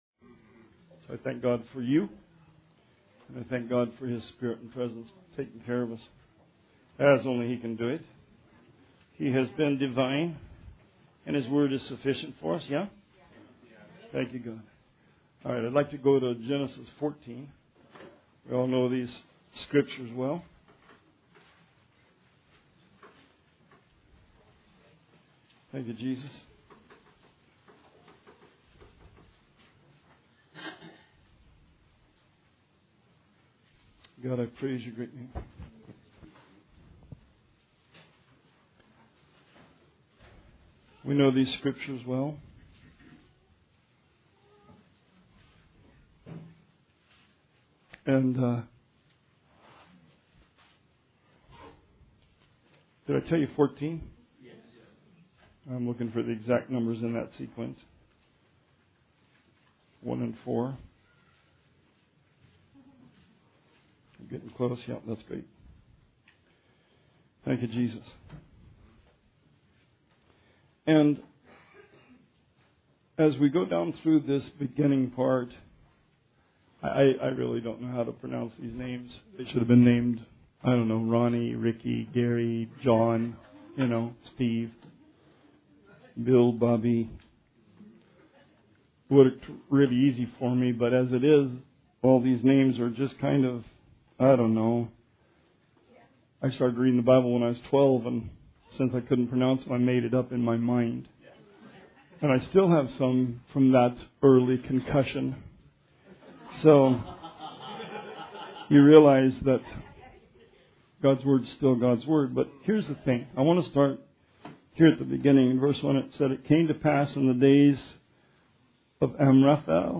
Sermon 6/16/19